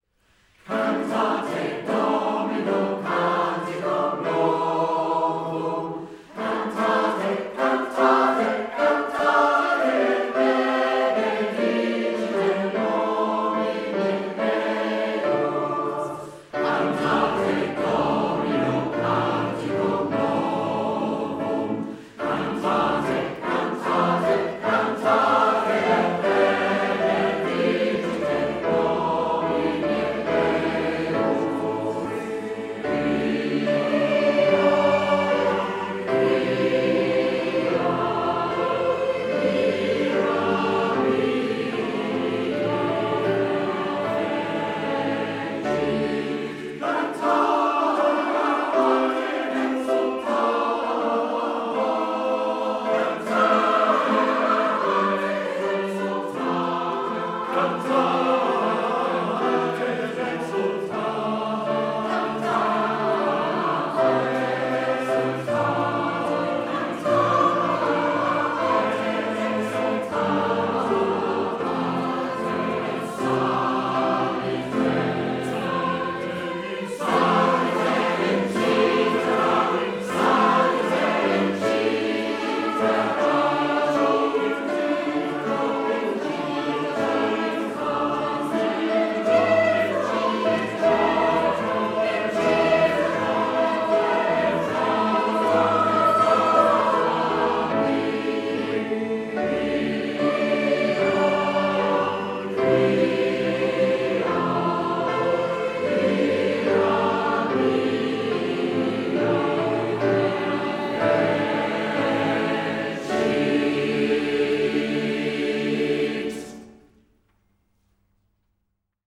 Recorded by individual members in their homes during lockdown